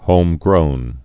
(hōmgrōn)